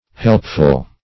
Helpful \Help"ful\, a.